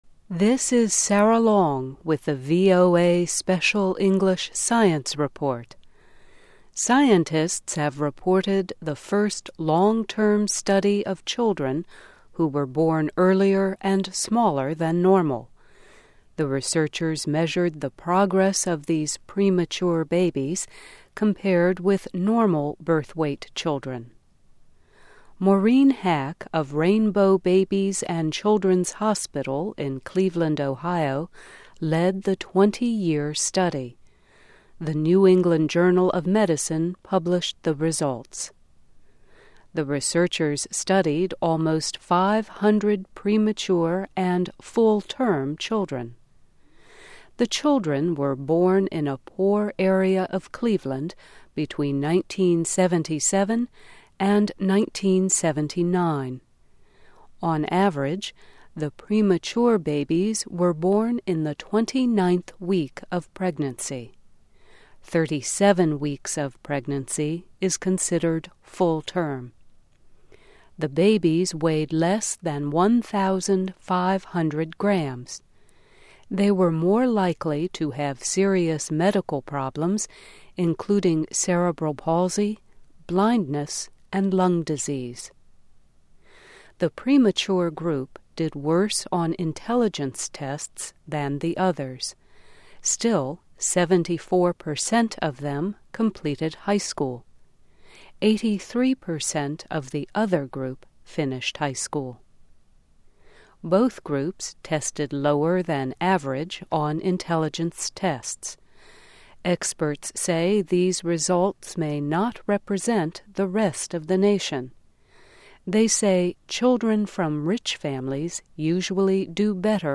Medical: Premature Baby Study (VOA Special English 2002-01-30)